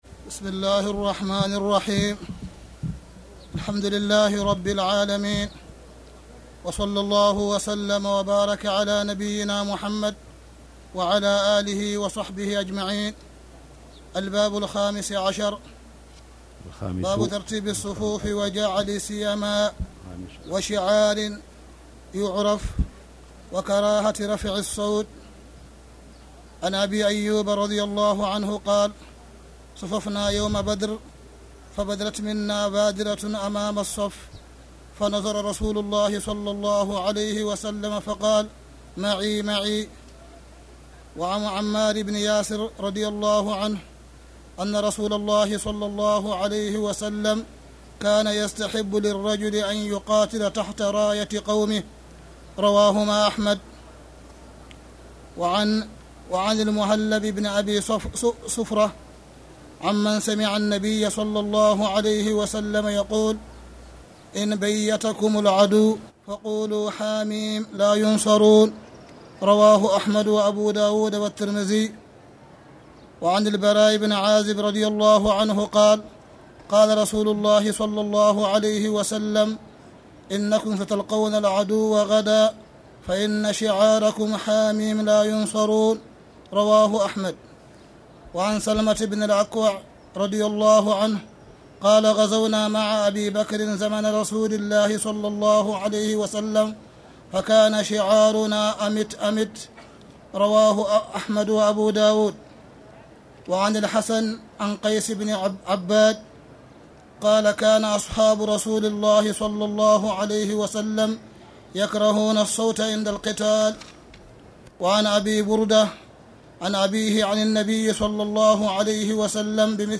تاريخ النشر ٤ رمضان ١٤٣٧ هـ المكان: المسجد الحرام الشيخ: معالي الشيخ أ.د. صالح بن عبدالله بن حميد معالي الشيخ أ.د. صالح بن عبدالله بن حميد نيل الاوطار كتاب الجهاد (3)